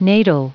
Prononciation du mot natal en anglais (fichier audio)
Prononciation du mot : natal